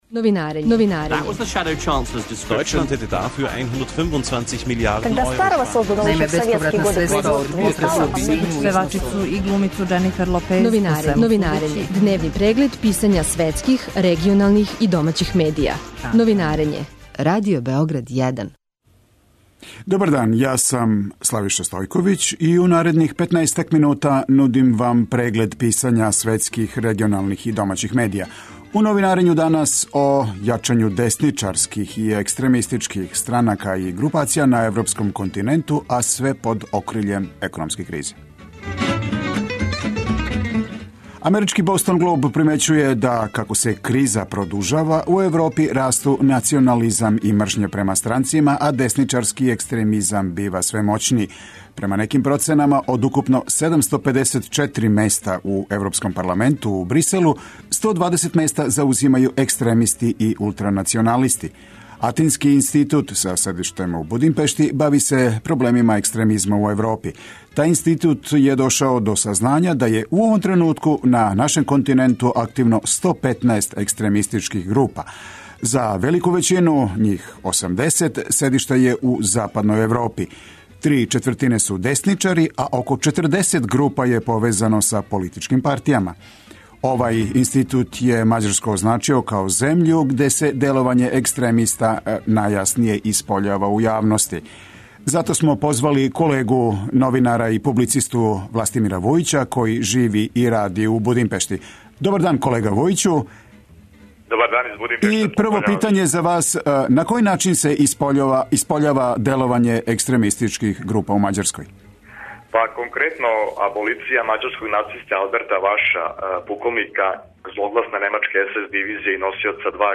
Тема је пораст национализма и десничарског екстремизма у Мађарској. Објављујемо и уобичајени преглед писања страних, регионалних и домаћих медија.